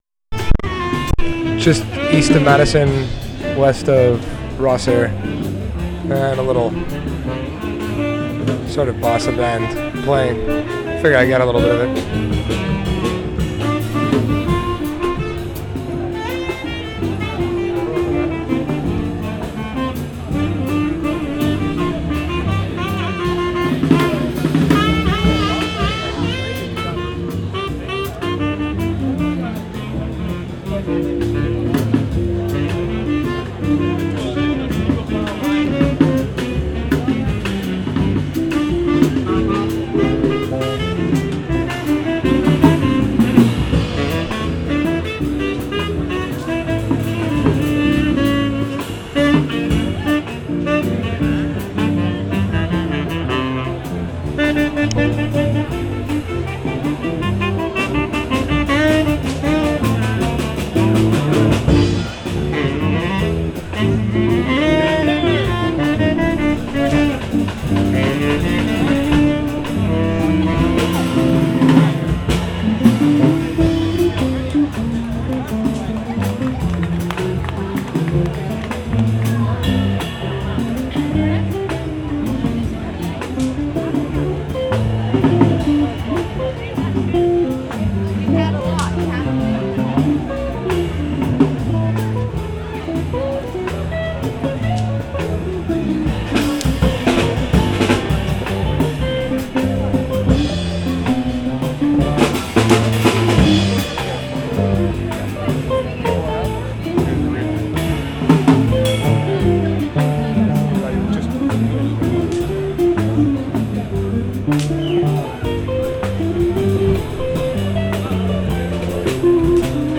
HATS OFF DAY (Hastings St, Burnaby), JUNE 5/2010
Hats off Bossa Band, 4:06
Track ID @ intro, East of Madison & West of Rosser, bossa band performance - beginning with a sax solo, 4 piece band (guitar, sax, bass, drum), crowd gathered in front listening, hundreds of people walking past (keynote of voices), guitar solo begins at aprox 1:15, ends at aprox 2:50 - band move back into the head and finish the tune ("Tangerine" by Johnny Mercer - an old jazz standard) at 3:55.